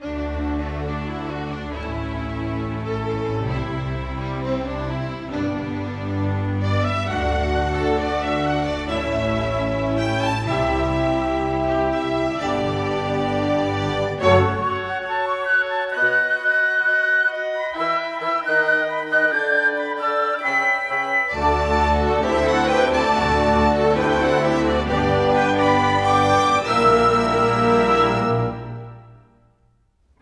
Rock-Pop 01.wav